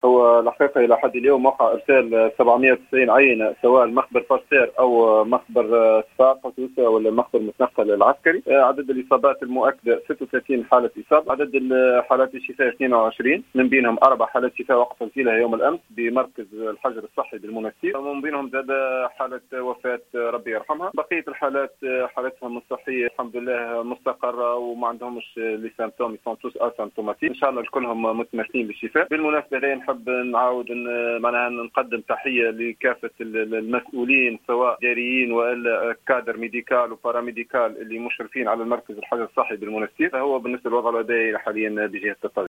أكد المدير الجهوي للصحة بتطاوين، ابراهيم غرغار في تصريح اليوم لـ"الجوهرة أف أم" استقرار الوضع الوبائي في الجهة وعدم تسجيل إصابات جديدة بفيروس "كورونا".